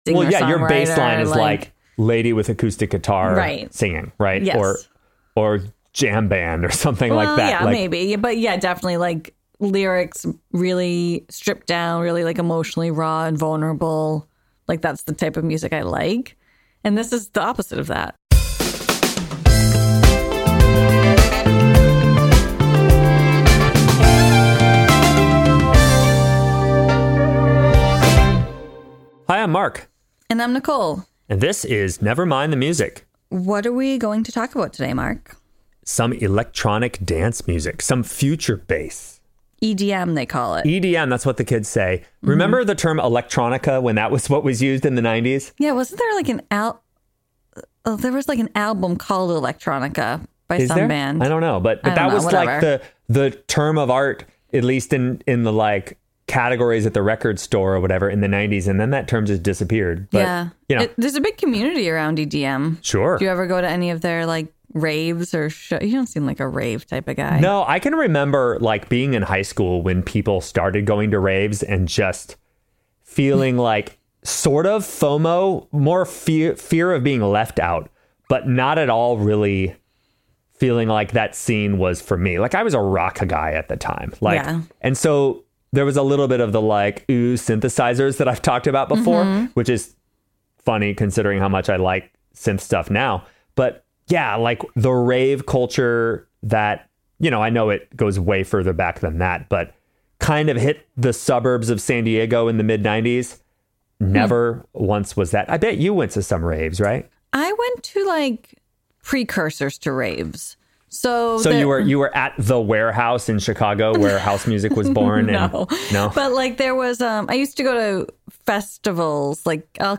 Join us each week as two friends, one a music professor and one a psychology professor, hang out and deep dive about one iconic song. Laugh and learn with us about songwriting, the brain, and pop culture.